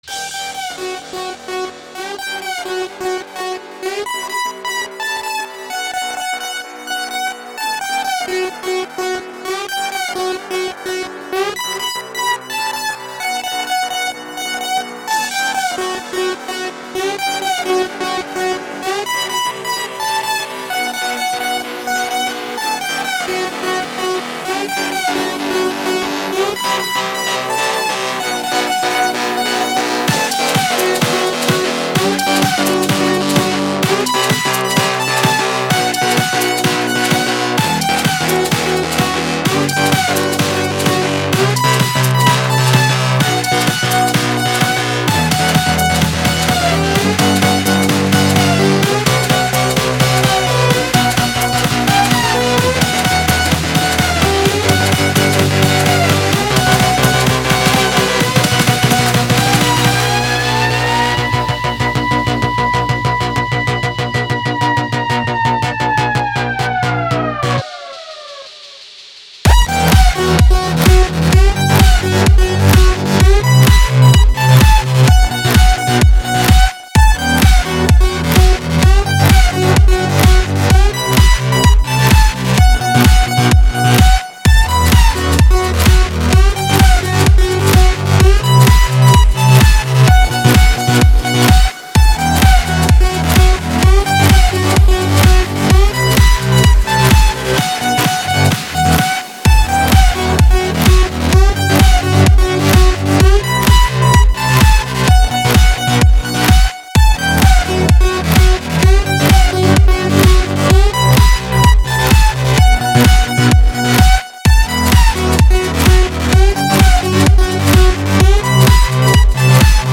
Категория: Рок, Альтернатива